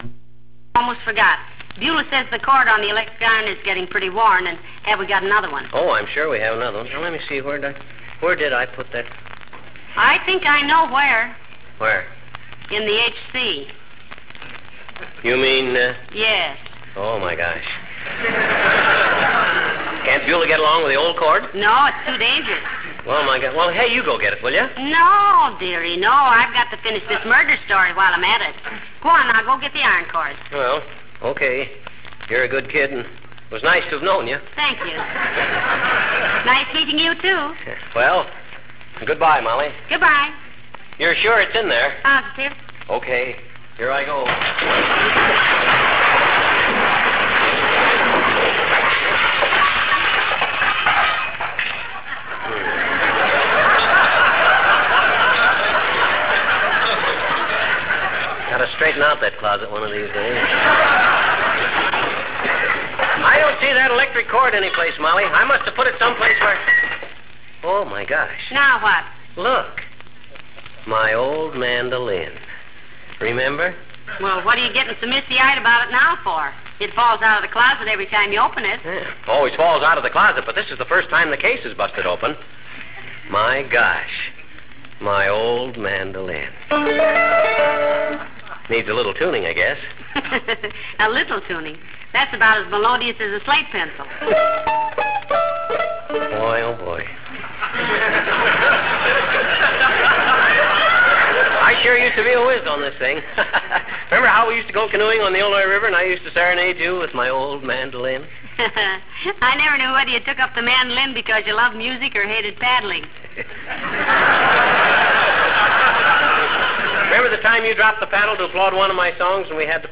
And then there was that closet...The legendary hall closet, piled high with a lifetime of mementos, knickknacks, and junk, made its first appearance in 1940. You could hear it all, invariably climaxed by the tinkling of a tiny dinner bell.